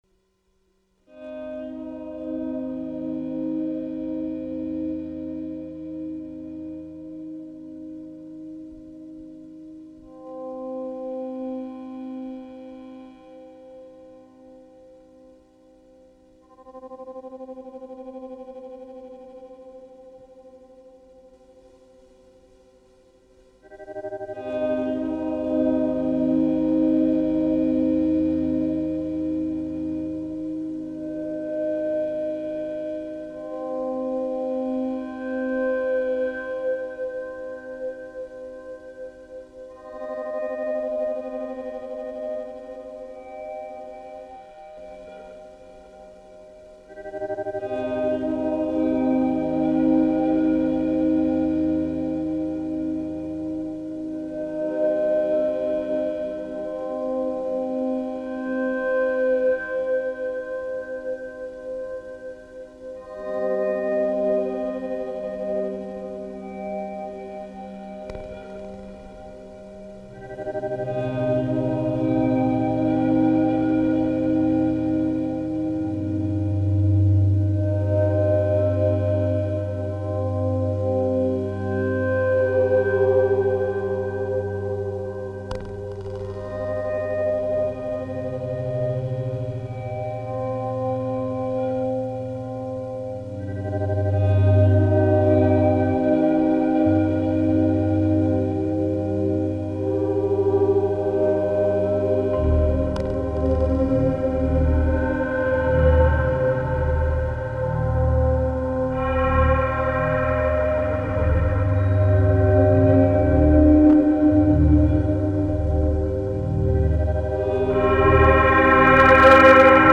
processed guitar magick